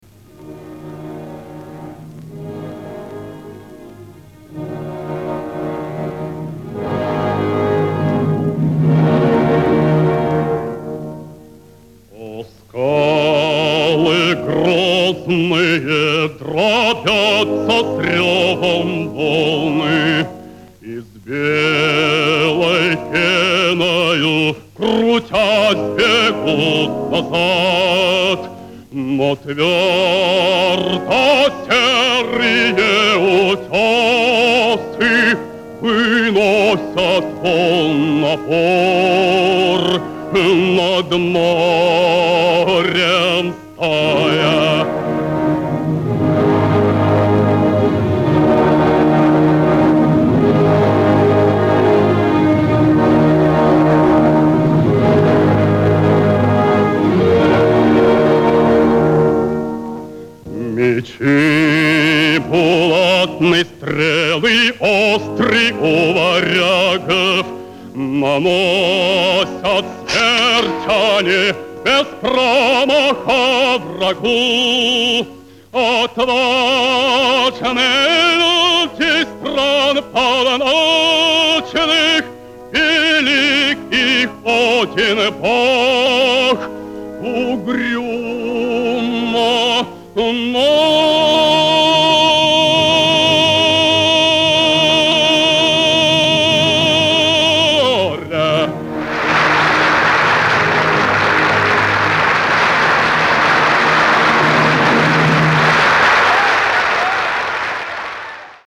Александр Огнивцев - Песня Варяжского гостя (Н.А.Римский-Корсаков. Садко. Запись 1953 года).mp3